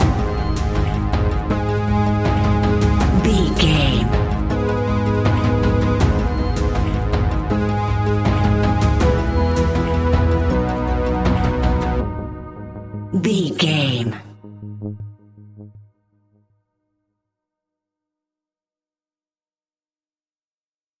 Aeolian/Minor
G#
ominous
dark
eerie
driving
synthesiser
brass
horror music